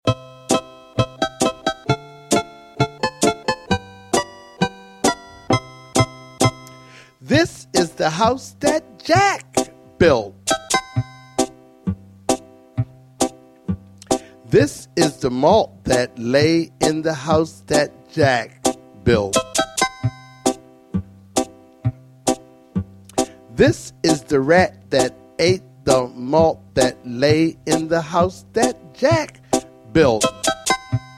Children's Nursery Rhyme and Sound Clip